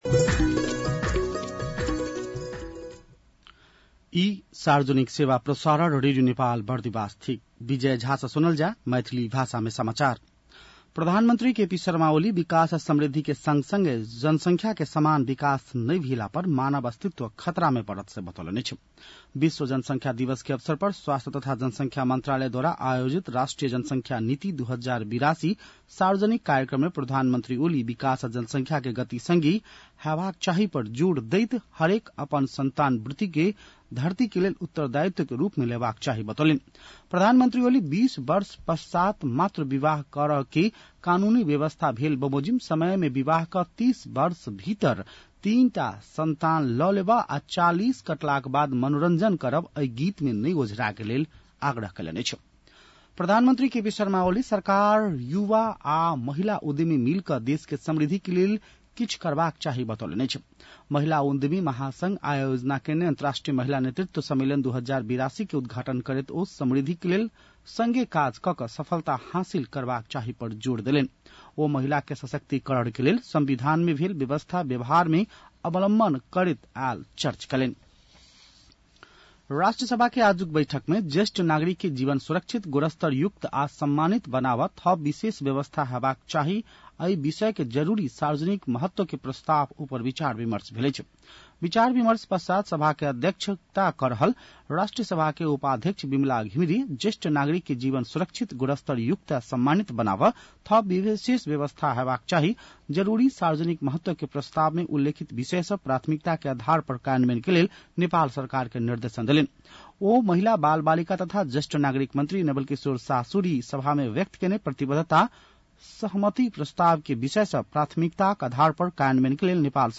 मैथिली भाषामा समाचार : २७ असार , २०८२
Maithali-news-3-27.mp3